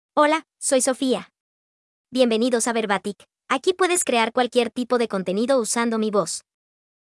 Sofia — Female Spanish AI voice
Sofia is a female AI voice for Spanish (Bolivia).
Voice sample
Female
Spanish (Bolivia)
Sofia delivers clear pronunciation with authentic Bolivia Spanish intonation, making your content sound professionally produced.